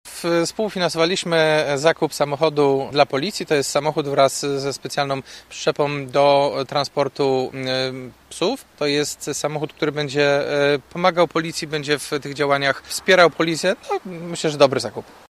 ’- Przekazaliśmy na ten cel 65 tysięcy złotych – mówi prezydent Jacek Wójcicki: